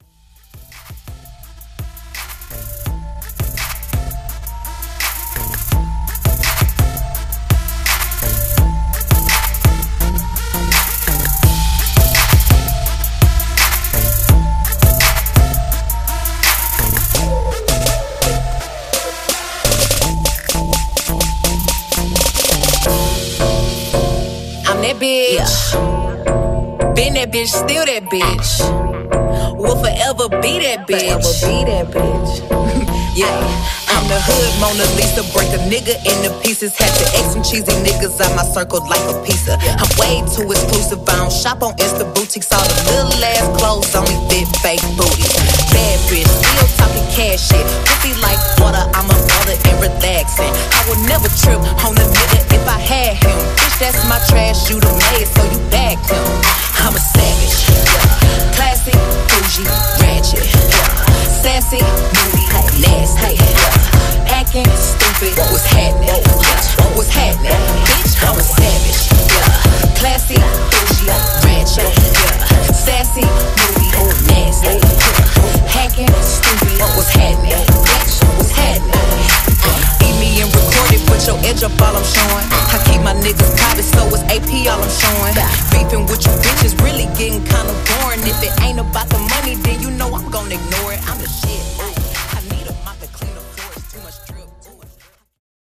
2020 HipHop Twerk Reload